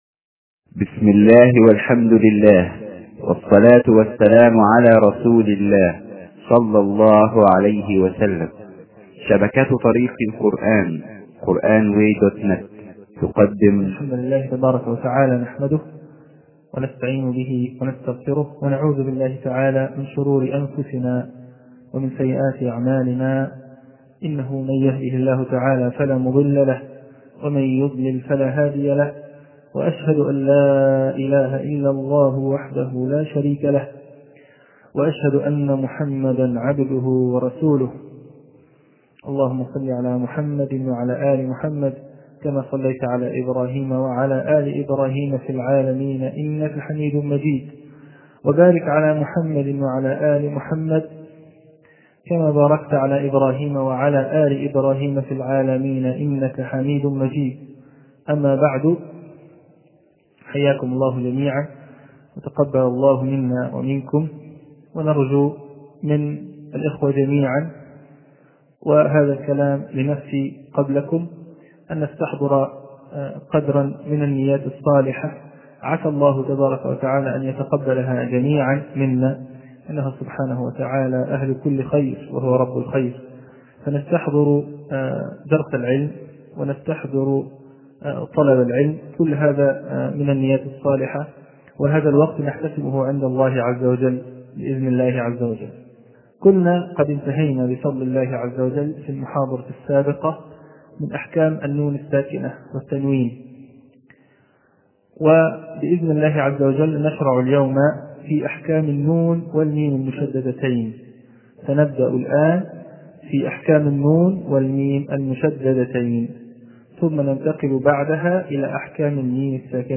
الدرس السادس - المكتبة الإسلامية